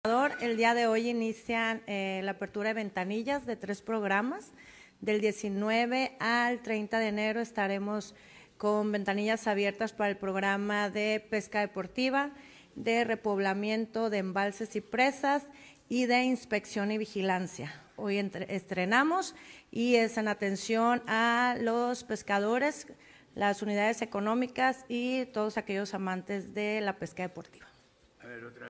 Guasave, Sinaloa, a 19 de enero de 2026.– Durante la conferencia “La Semanera”, encabezada por el gobernador del estado, Dr. Rubén Rocha Moya, la titular de la Secretaría de Pesca y Acuacultura (SPyA), Flor Emilia Guerra Mena, informó la apertura del periodo de ventanillas para diversos programas de apoyo al sector pesquero y acuícola, a partir de este lunes 19 de enero: